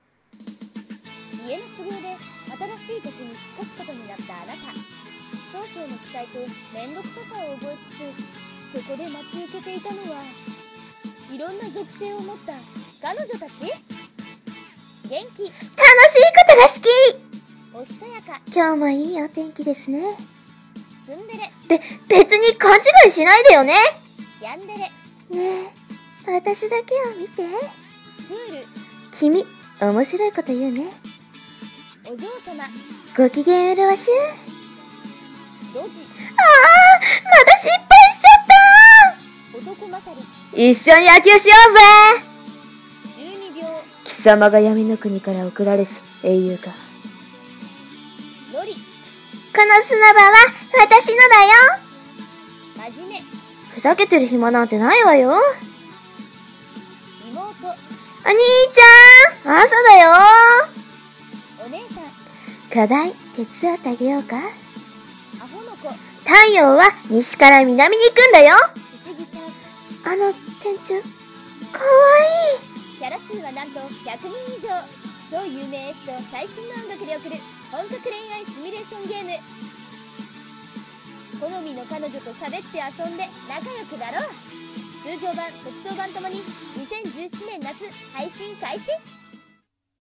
【ゲームCM風声劇】属性彼女っ♪